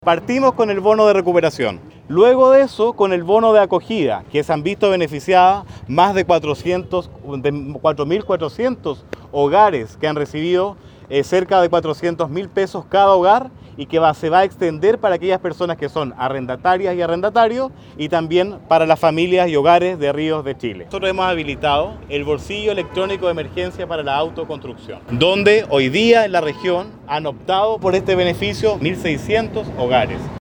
Quien también estaba presente en terreno fue el subsecretario de Gobierno, Erwin Díaz, quien aseguró que las ayudas se han desplegado desde el pasado 21 de enero.